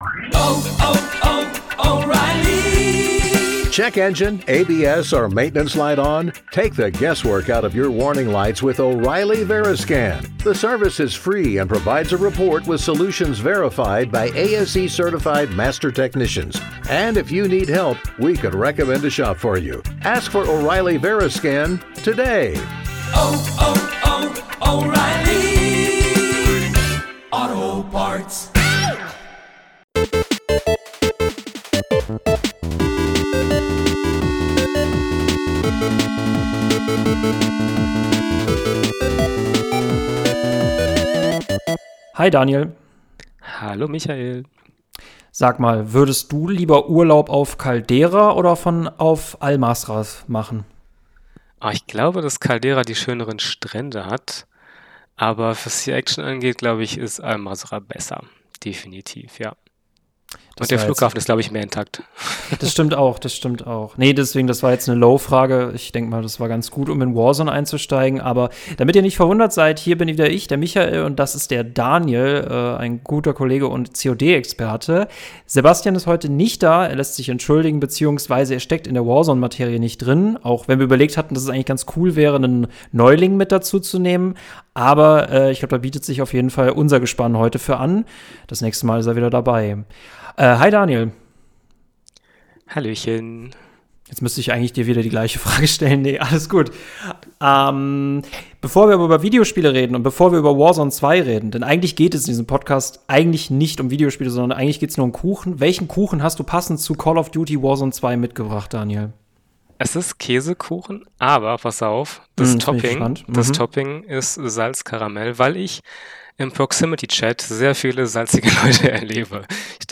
Aufgrund der schlechten Tonqualität der ersten Uploads hier nochmal unser letzter Sonntagspodcast in noch besser :) Holt euch einen Kaffee